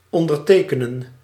Ääntäminen
Tuntematon aksentti: IPA : /saɪn/